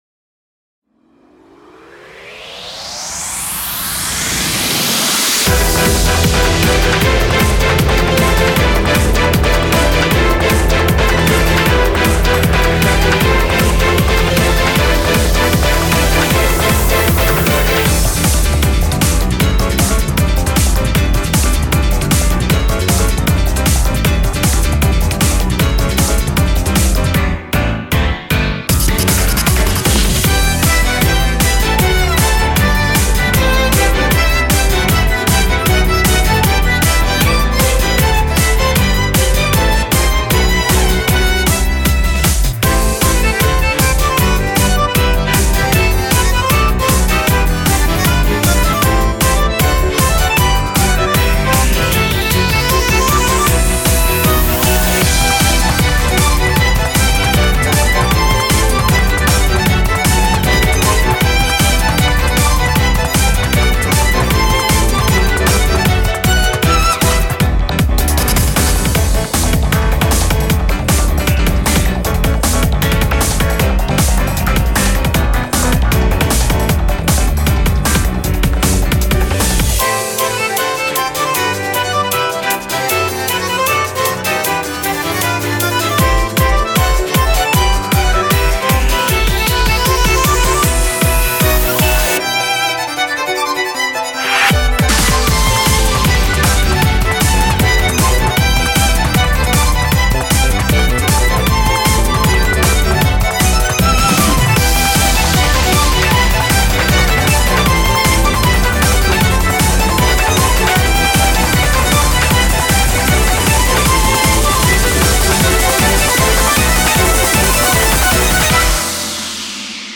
BPM155-155
Audio QualityMusic Cut
Lots of violin, and plenty of piano to spare.